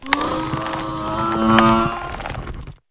klawf_ambient.ogg